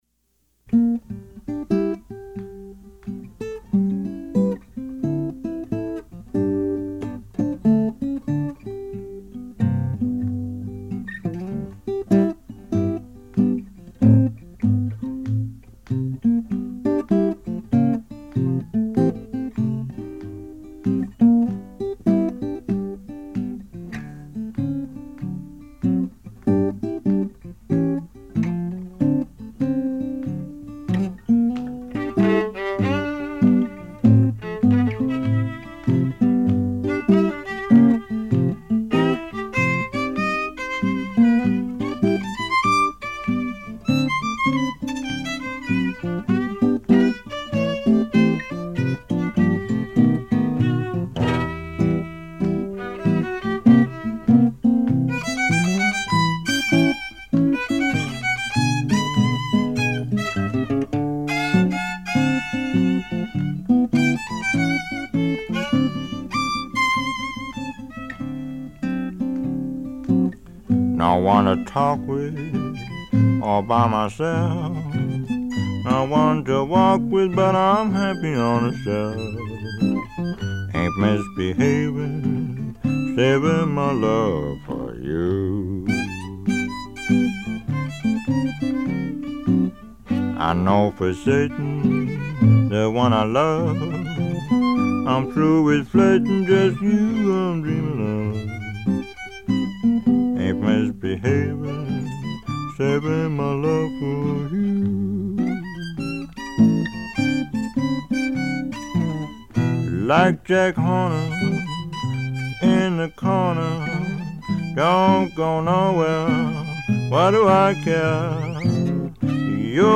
including blues, ragtime, dixieland jazz and country.